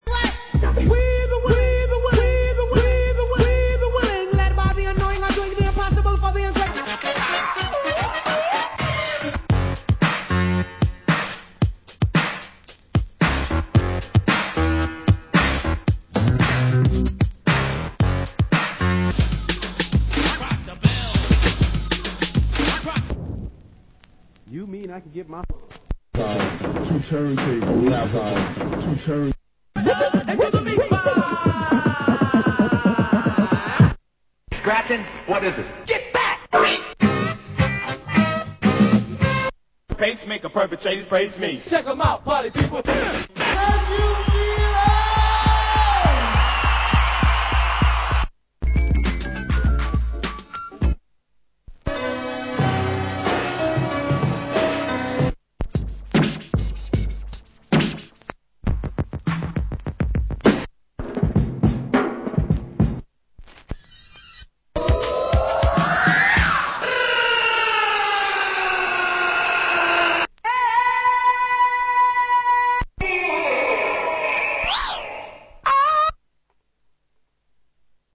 Format: Vinyl 12 Inch
Genre: DJ Turntablist Tools
DJ Toosl / Breaks / Sample